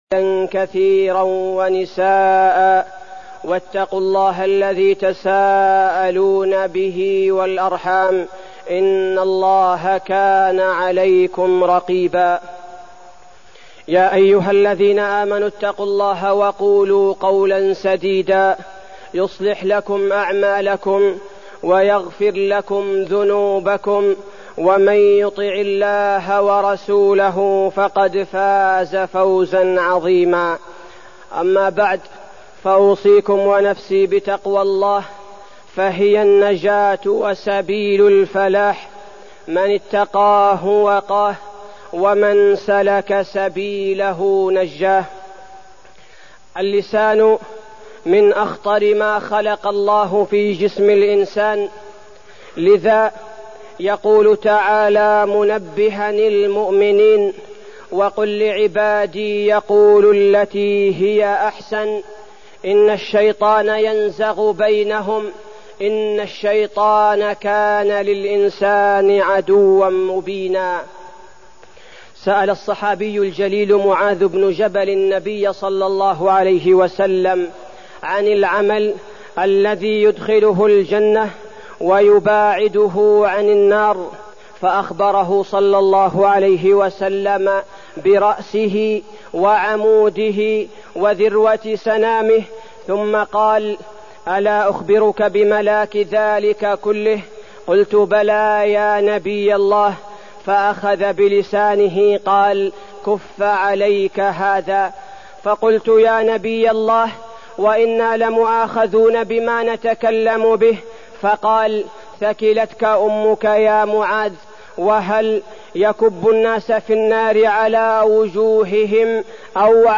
تاريخ النشر ١٠ رجب ١٤١٩ هـ المكان: المسجد النبوي الشيخ: فضيلة الشيخ عبدالباري الثبيتي فضيلة الشيخ عبدالباري الثبيتي آفات اللسان